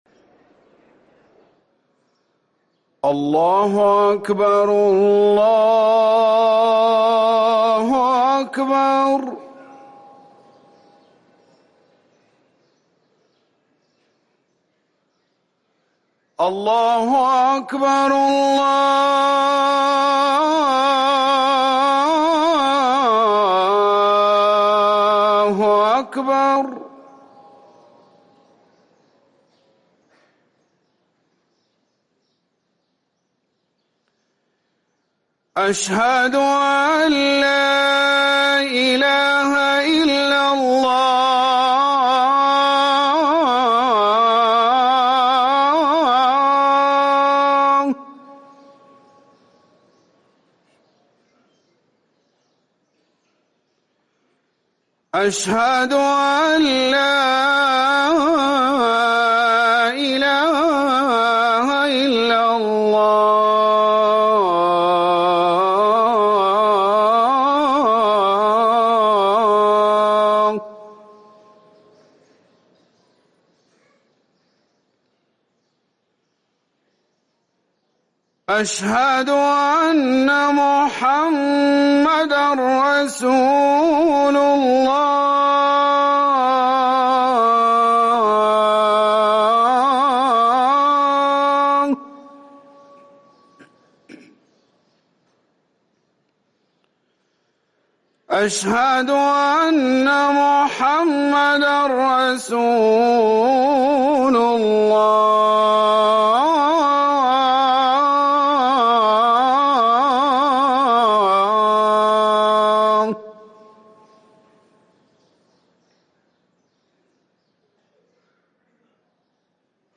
أذان العصر
ركن الأذان